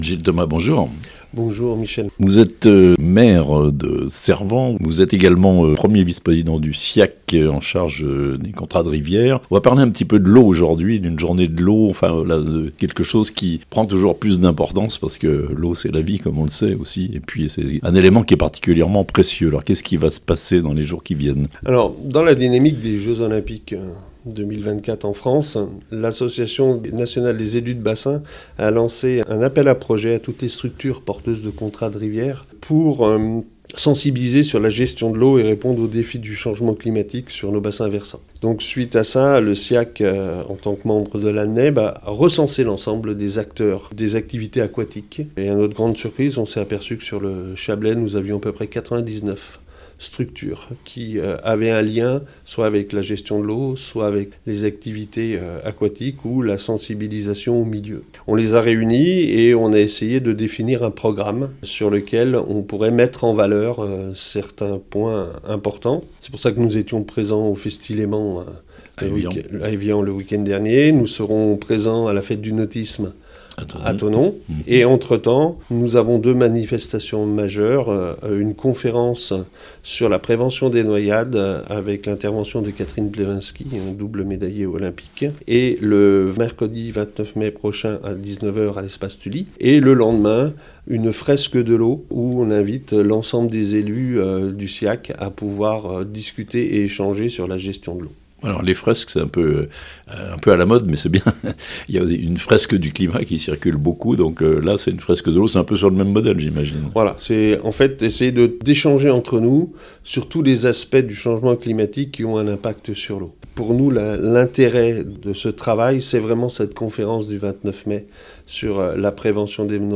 Thonon : une conférence sur la prévention des noyades et sur l'aisance aquatique (interview)
Présentation de cette conférence par Gil Thomas, Maire de Cervens et 1er Vice-président du SIAC.
itw-gil-thomas-siac-journees-de-l-eau-a-diffuser-6559.mp3